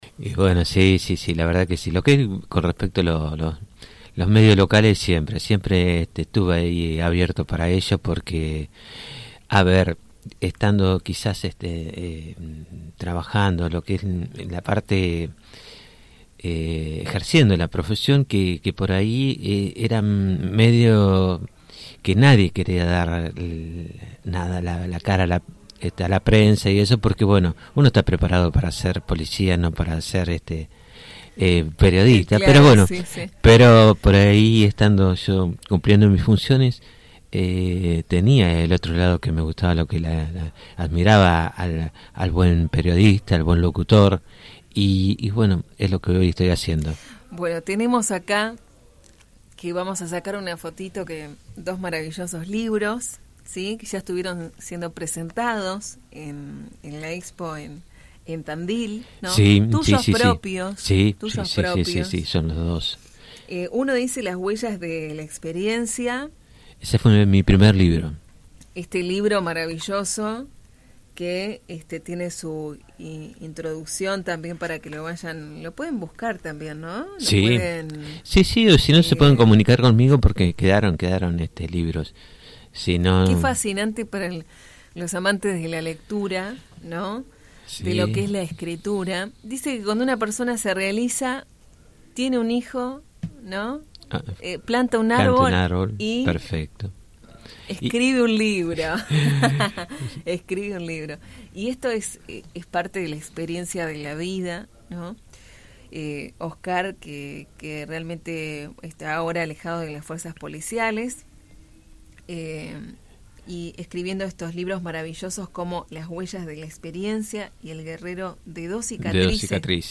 En visita a los estudios de FM Alpha